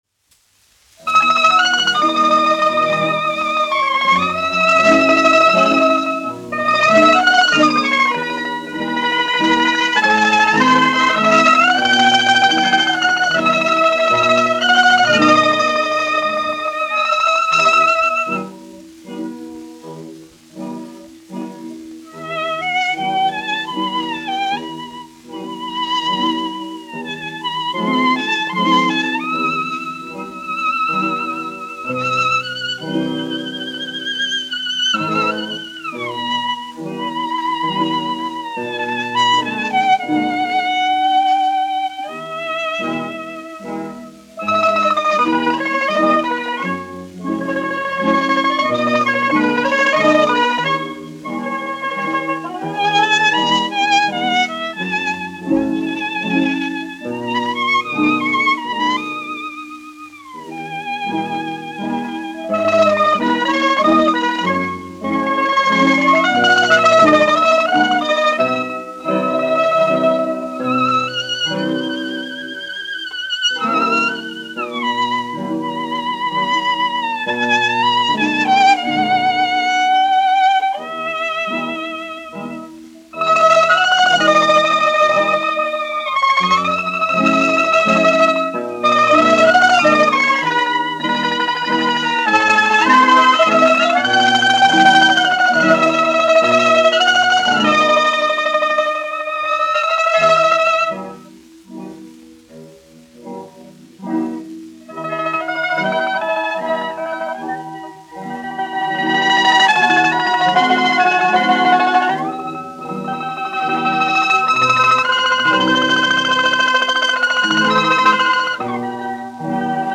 1 skpl. : analogs, 78 apgr/min, mono ; 25 cm
Populārā instrumentālā mūzika
Latvijas vēsturiskie šellaka skaņuplašu ieraksti (Kolekcija)